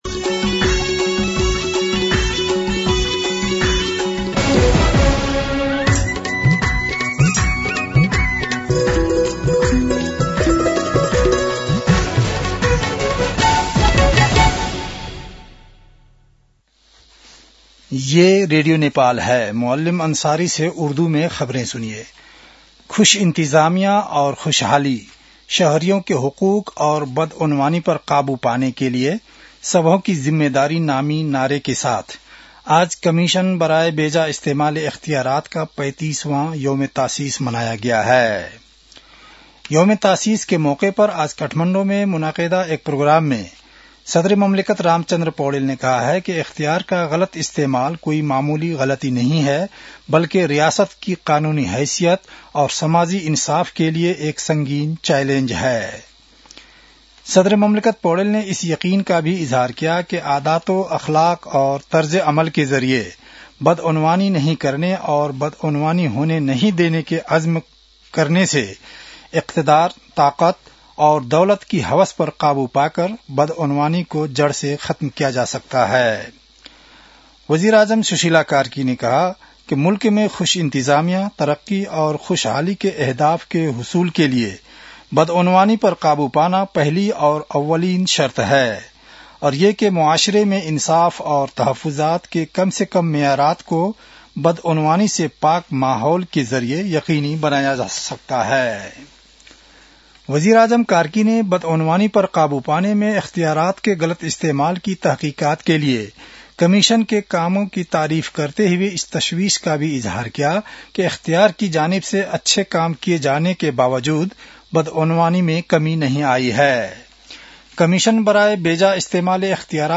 उर्दु भाषामा समाचार : २८ माघ , २०८२